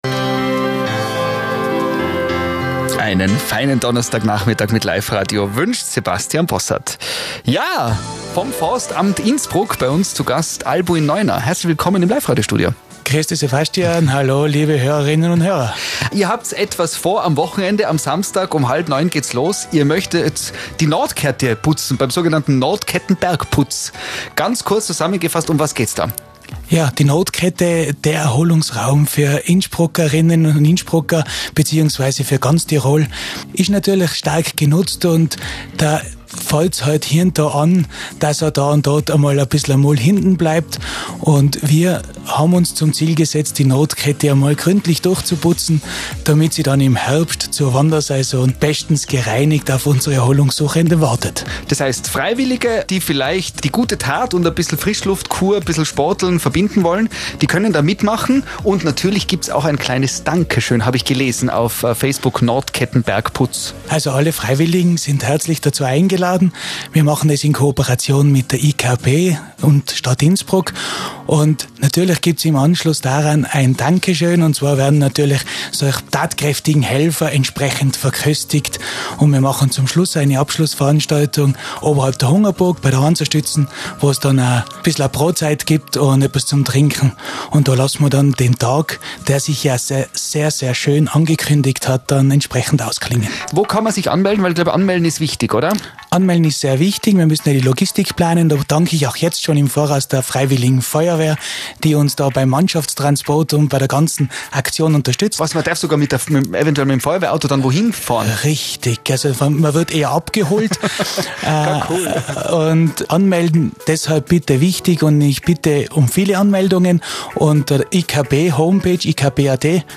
im Life-Radio zum Bergputz